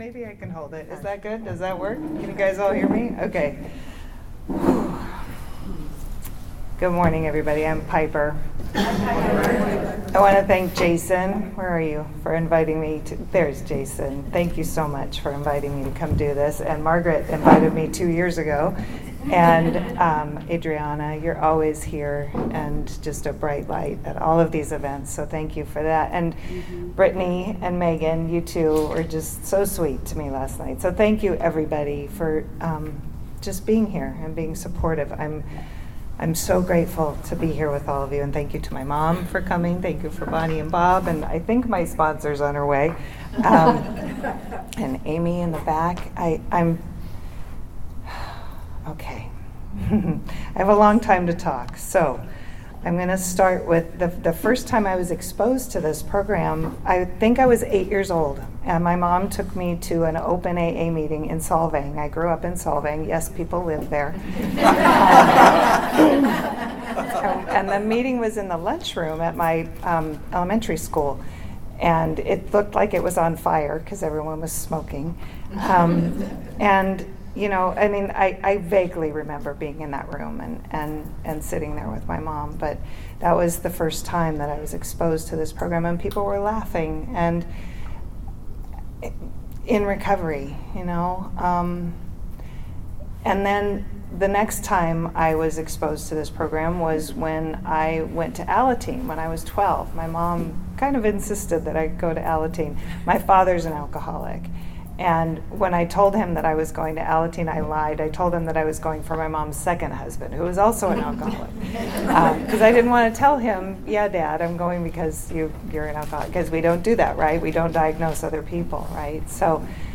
35th Annual Ventura Serenity By The Sea